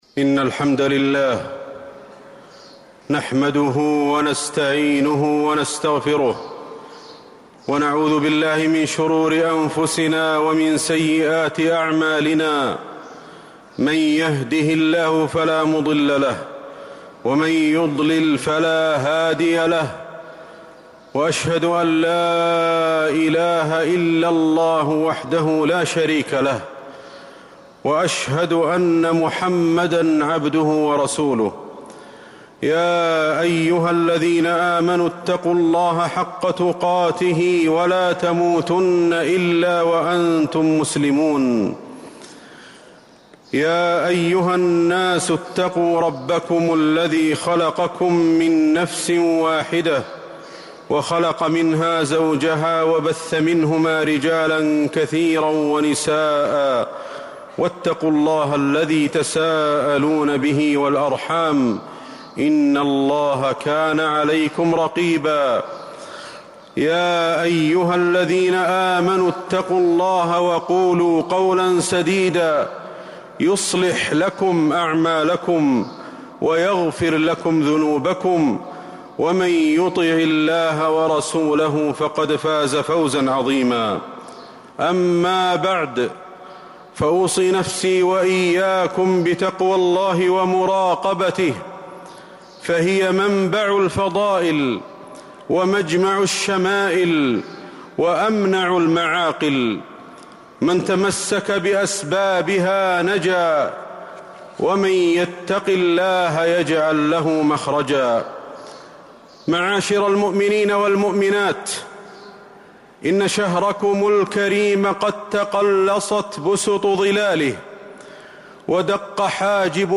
المدينة: في توديع رمضان - أحمد بن علي الحذيفي (صوت - جودة عالية. التصنيف: خطب الجمعة